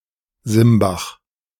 Simbach (German pronunciation: [ˈzɪmbax]
De-Simbach.ogg.mp3